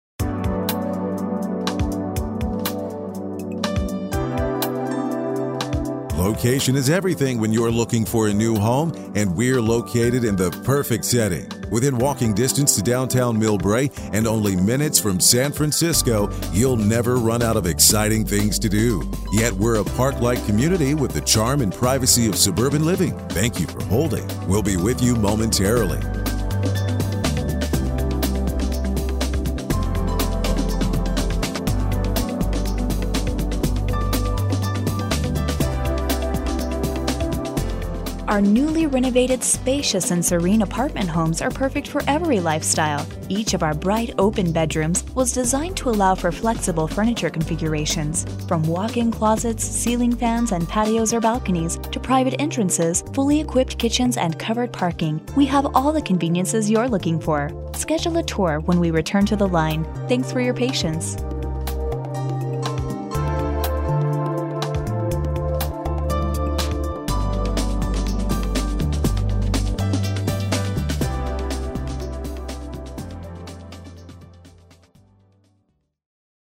Messages on hold
Music on hold